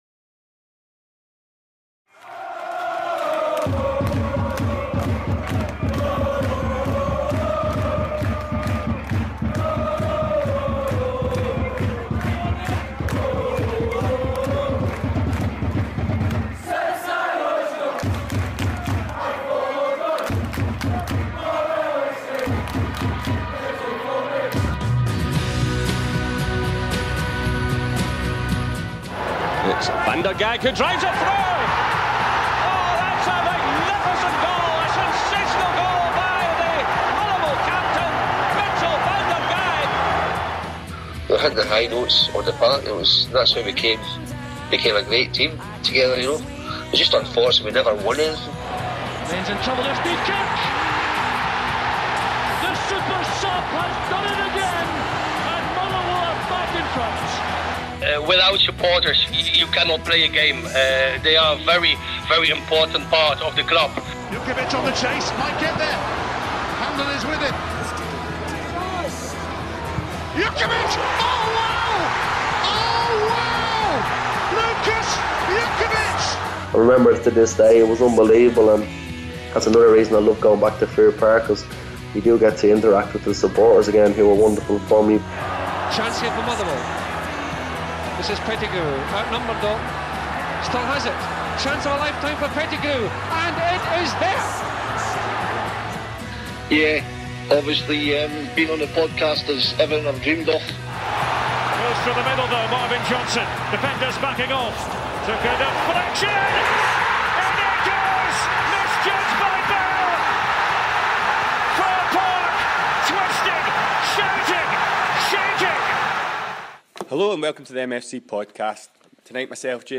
in the Centenary Suite at Fir Park